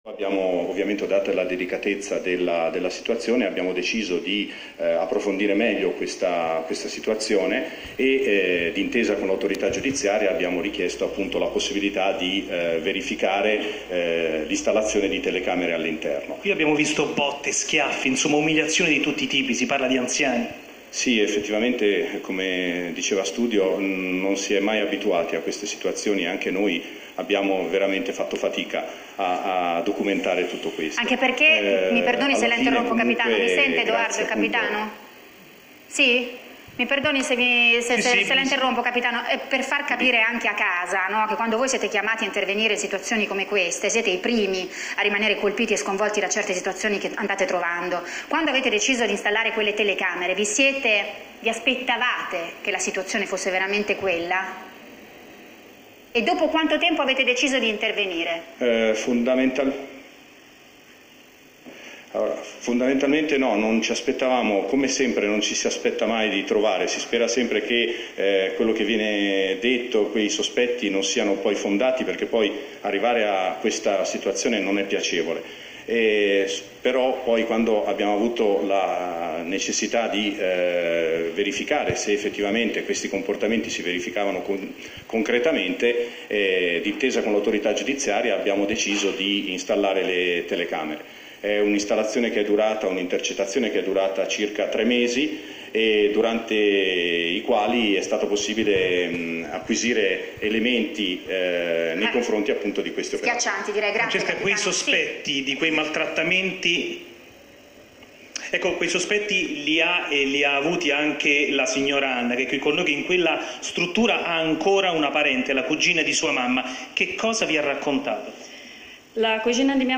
19 aprile 2018 – La vicenda riguardante la Casa di Riposo, di Castel San Niccolò, in provincia di Arezzo, dove sarebbero stati accertati abusi su almeno due pazienti anziani, un uomo di 85 anni ed una donna di 90, perpetrarti da sei operatori (cinque donne ed un loro collega) è stata trattata questo pomeriggio nel programma pomeridiano di Rai1 “La Vita in diretta”, nello spazio curato da Francesca Fialdini.